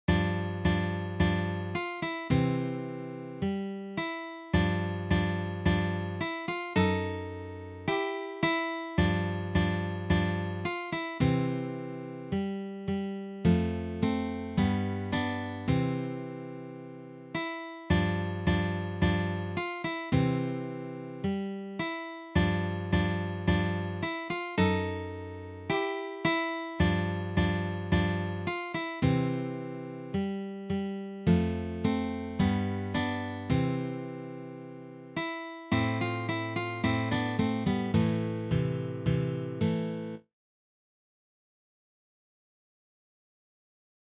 for three guitars
This is from the Classical period.